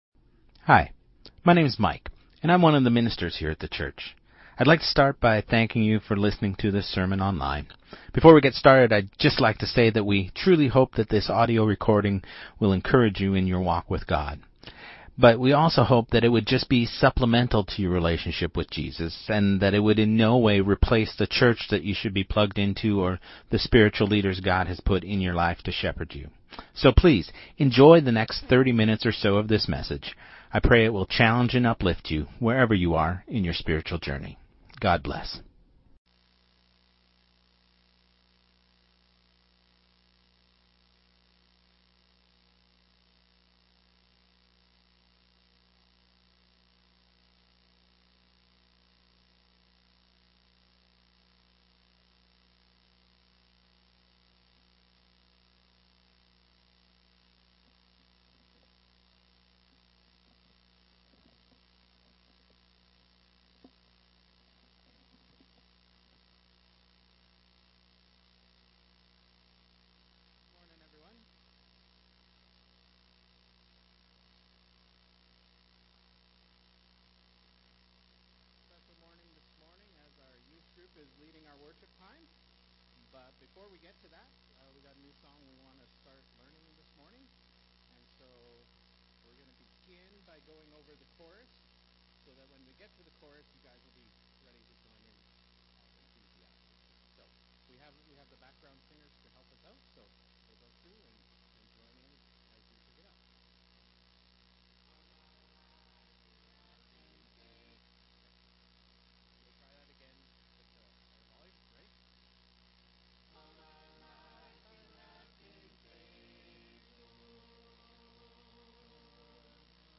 Sermon2026-02-08